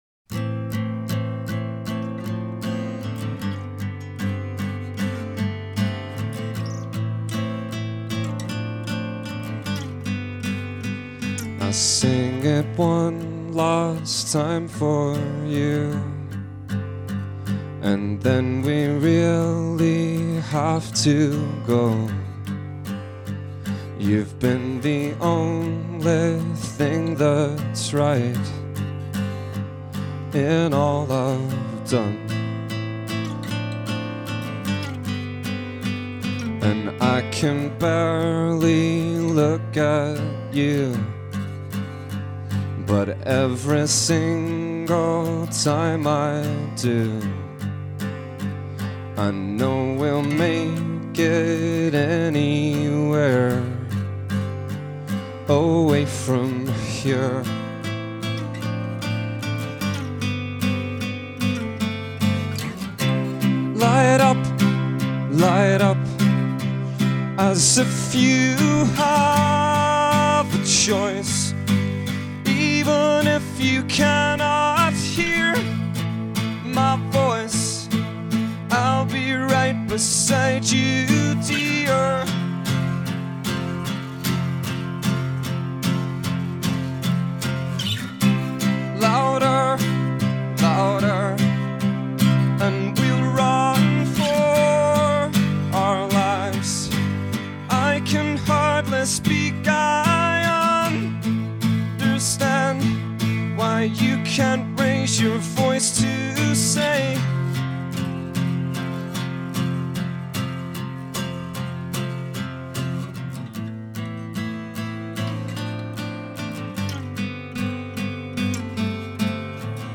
Acoustic